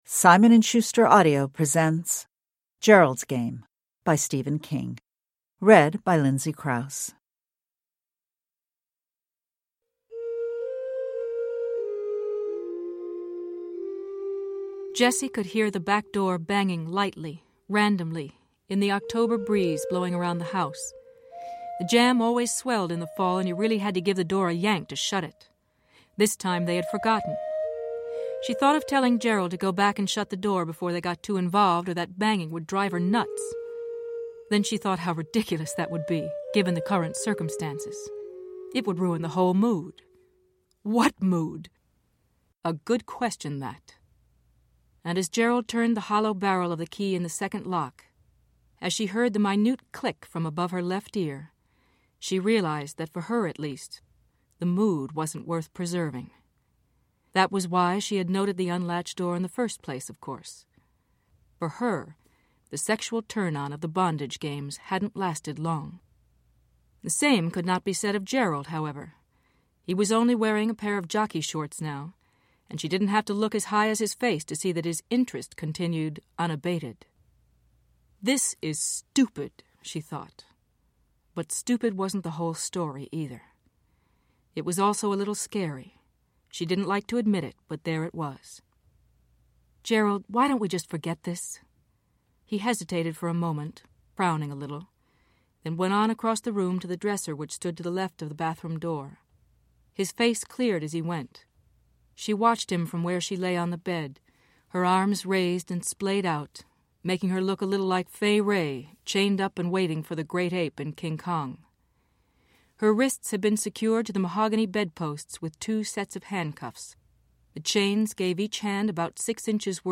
Gerald's Game – Ljudbok
Uppläsare: Lindsay Crouse